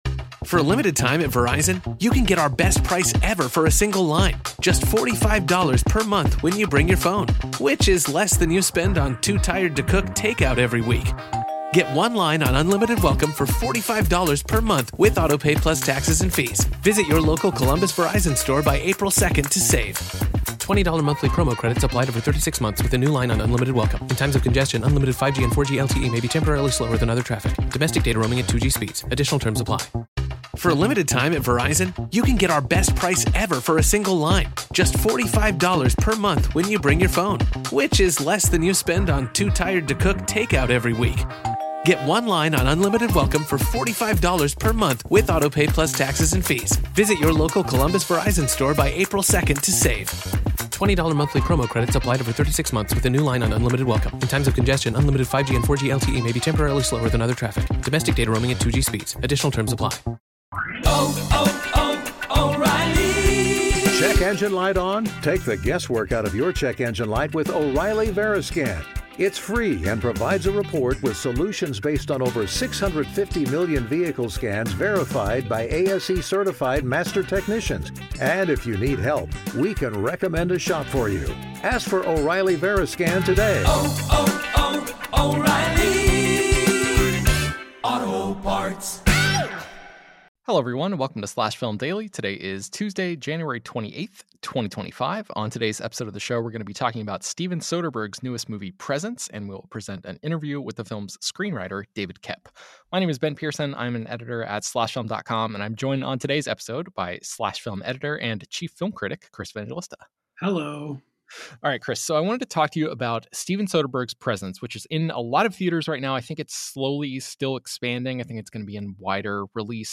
Presence is an Unconventional Ghost Story + Interview with Screenwriter David Koepp